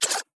Tab Select 5.wav